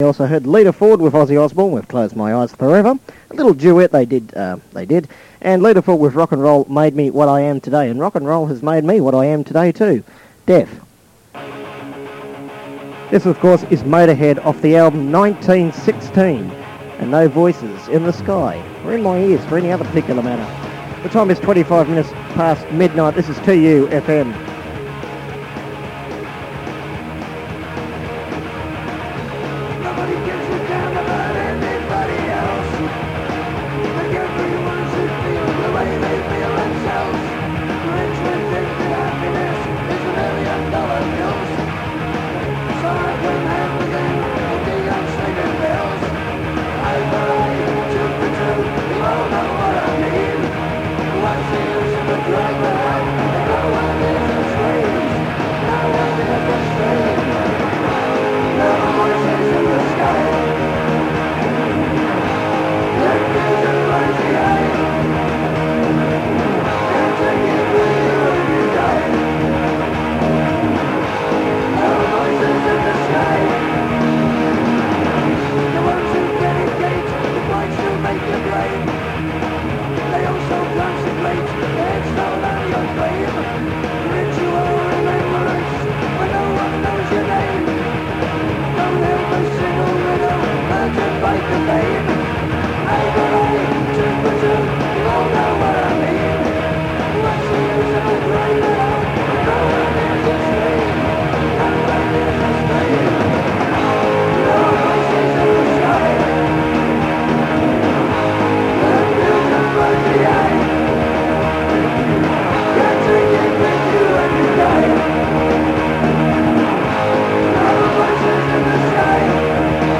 Most of the time, it was a radio/cassette player/recorder which had to be placed in another room at the station to prevent feedback, though one just had to remember to place your longer songs at the right times (or time it for news breaks) so you could switch sides or change cassettes.
The recordings are over 20 years old (in some cases, 25), and were recorded onto devices of varying quality, by machines of varying quality, they vary considerably in playability and listenability.